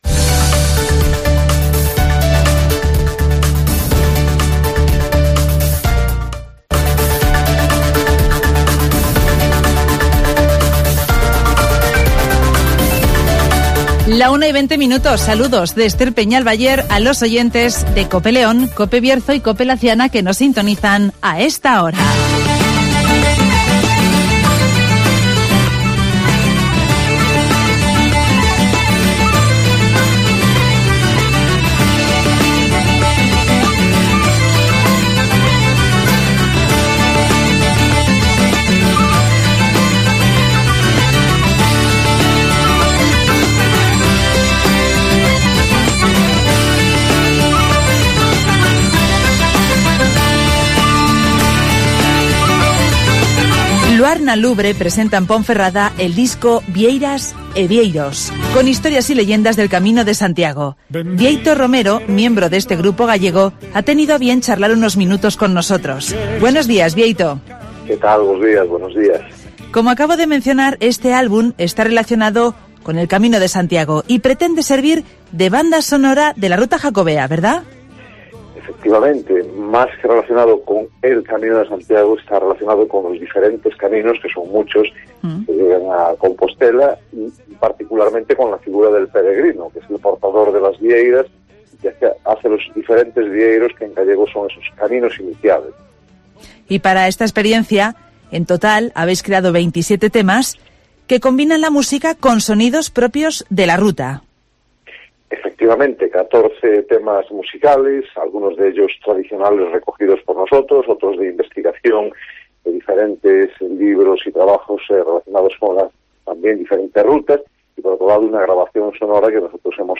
Luar Na Lubre presenta en el Bergidum su último trabajo ‘Vieiras e vieiros, historias de peregrinos’ (Entrevista